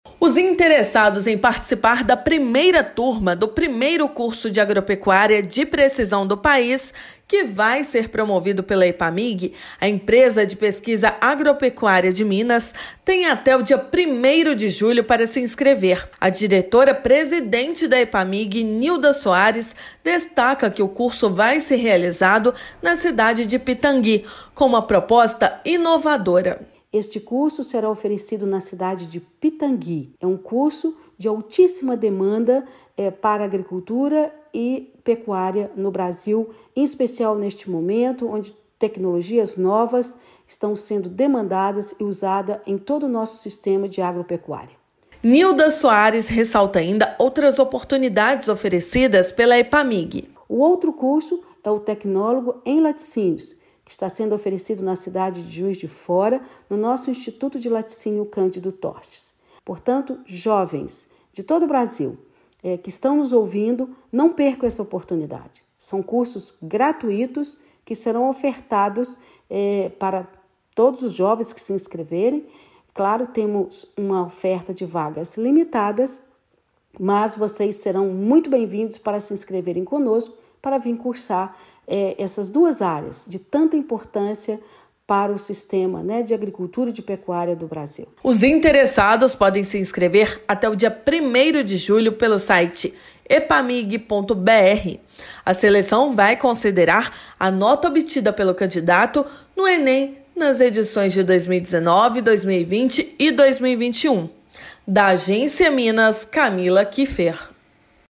Solenidade marca também a implantação do Instituto Tecnológico de Agropecuária de Pitangui (ITAP), na região Central. Ouça matéria de rádio.